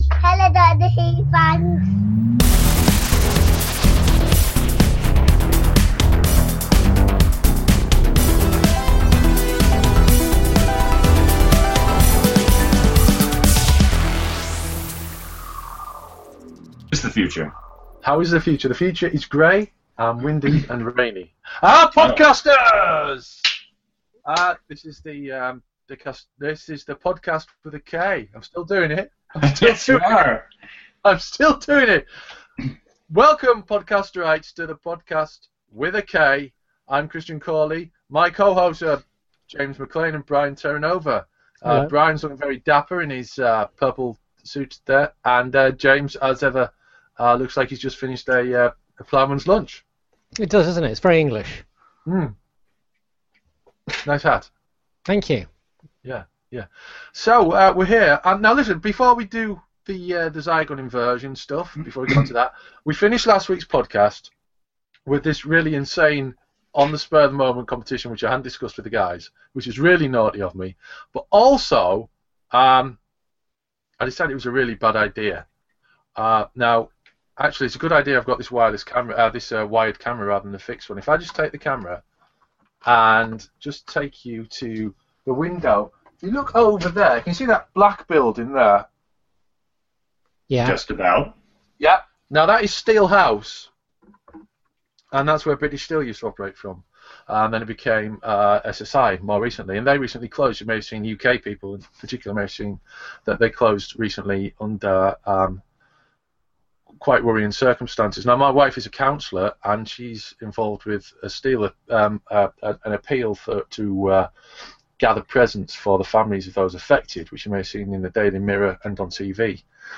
was live on Google Hangouts